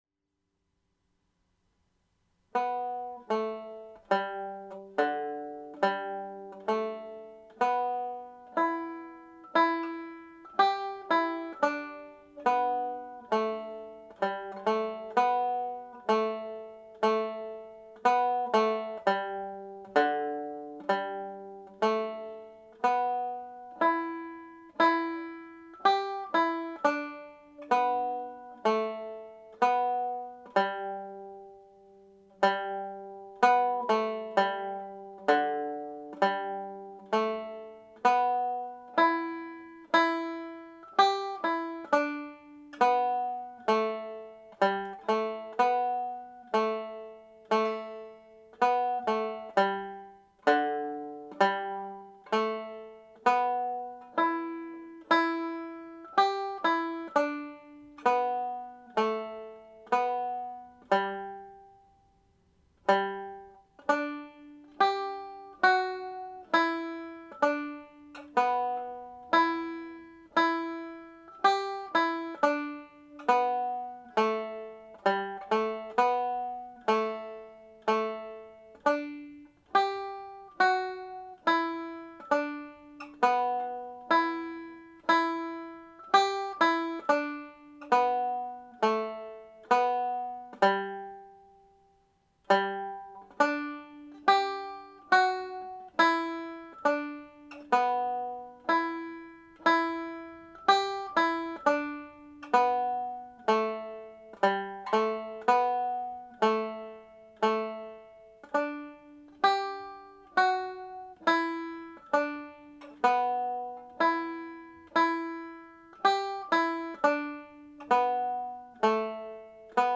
Maggie In The Wood played slowly
maggie-in-the-wood_slow_Maggie-in-the-woods-slow.mp3